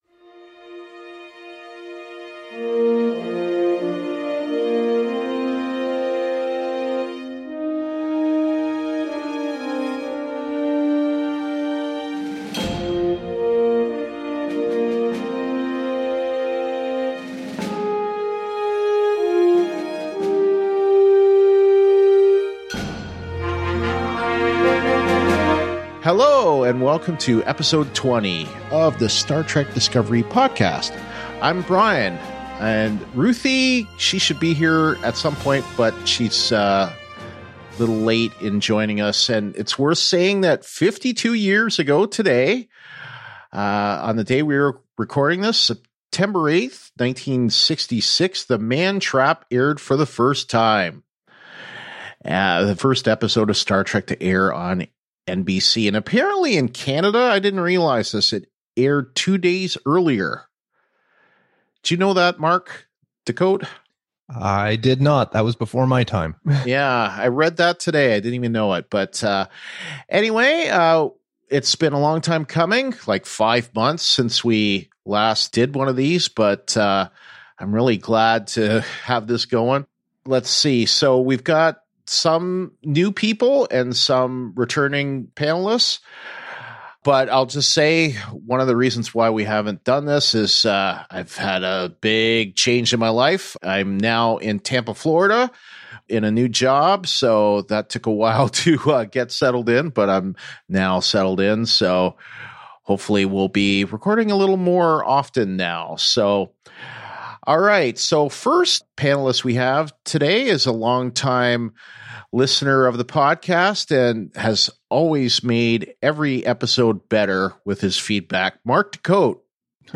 Star Trek: Discovery – Season 2 and Picard Show News Roundtable!
This episode took a while to get released, due to some glitches that were encountered during the recording, some we didn’t know about at the time.